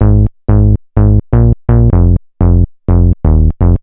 studio electronics se1x щелчки при затухании ноты
Побороть щелчки можно если релиз сделать длинным, но хочется короткие ноты использовать и прерывистые басы(( Вложения Studio Electronics Se-1X.wav Studio Electronics Se-1X.wav 1,3 MB · Просмотры: 258